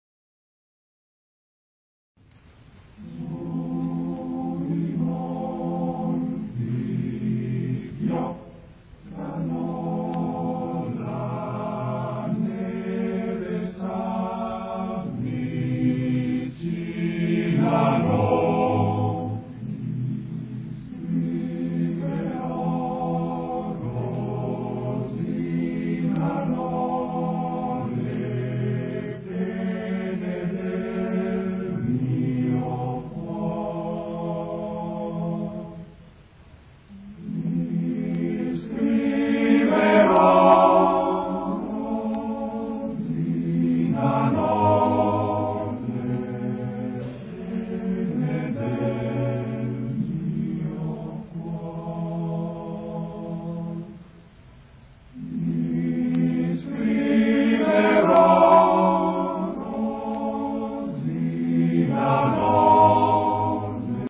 Sui monti fioccano [ voci virili ] Su i monti fioccano, la neve s’avvicinano ti scriverò Rosinano le pene del mio cuor.